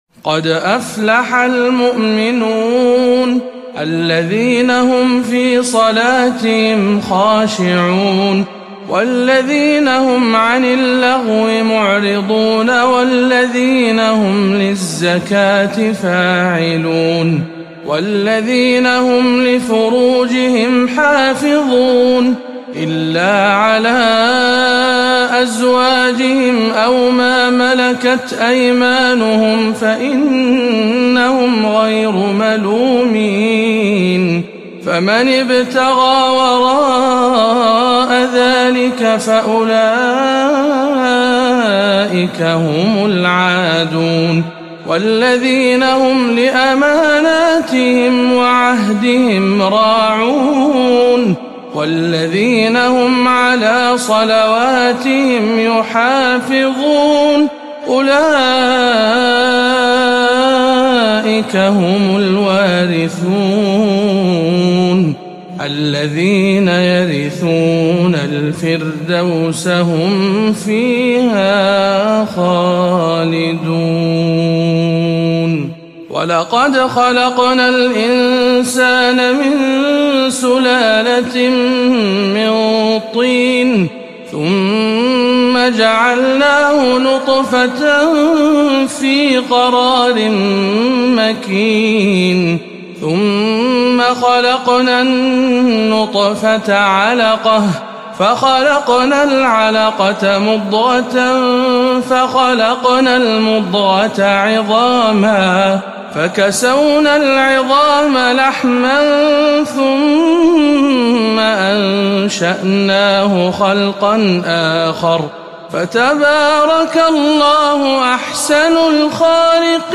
سورة المؤمنون بجامع أجور بالبحرين - رمضان 1438 هـ